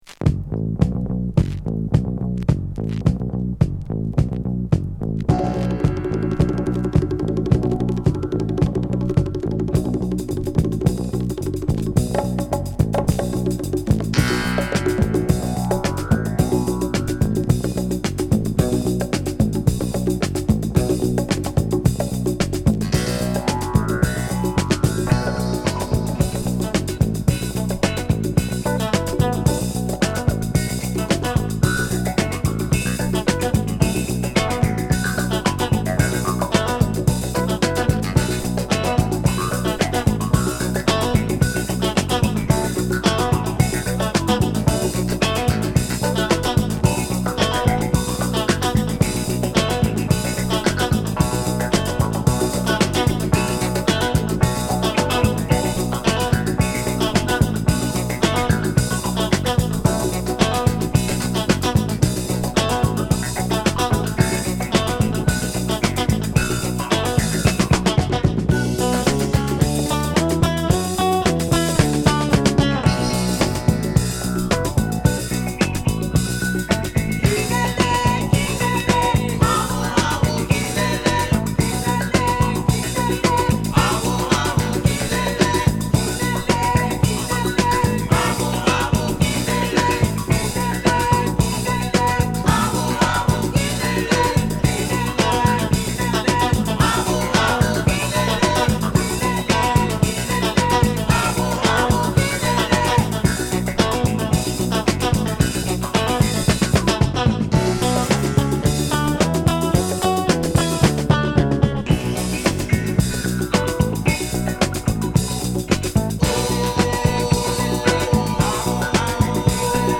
魅惑のカリブ海クロスオーバー。